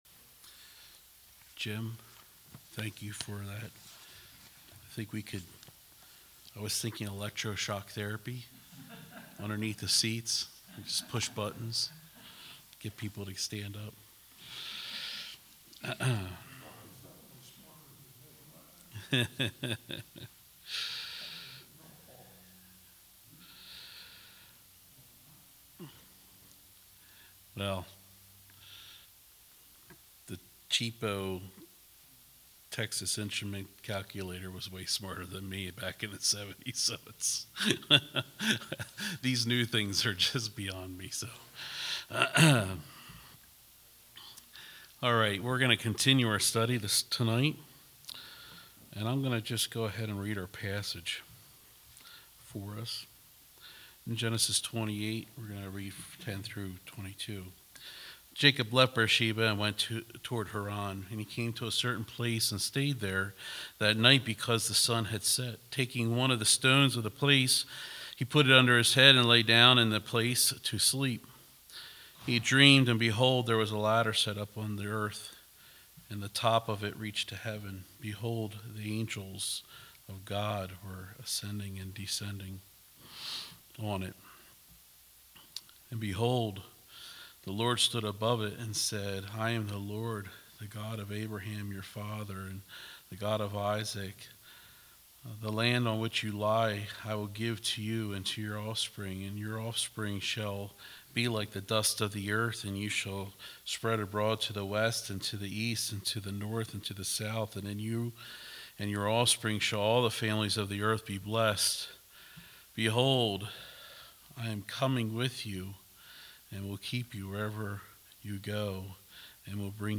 All Sermons Genesis 28:10-22